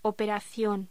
Locución: Operación
voz